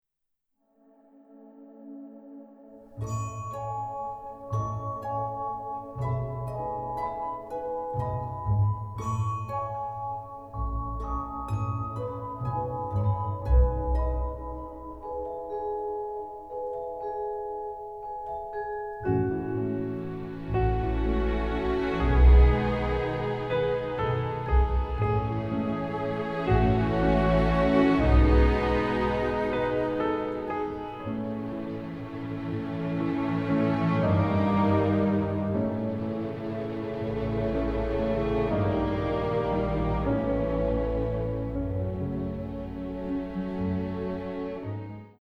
delightfully emotional
full of beautiful and delicate melodies